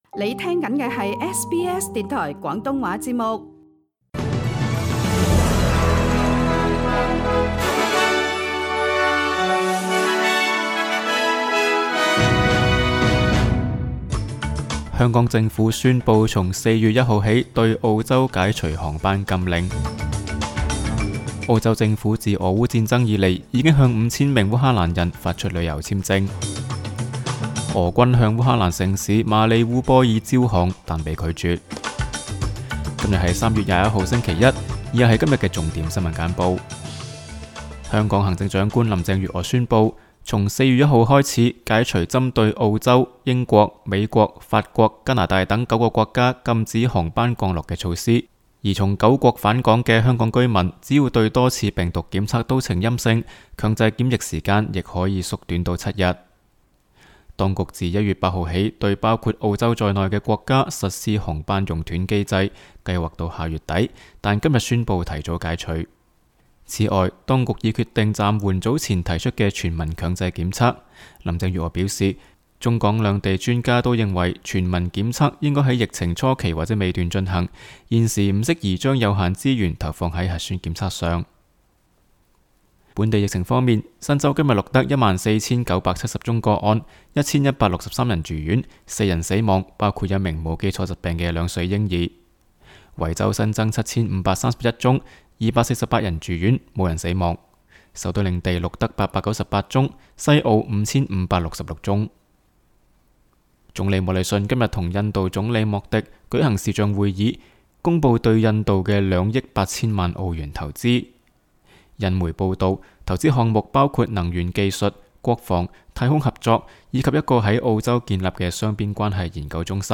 请收听本台为大家准备的每日重点新闻简报。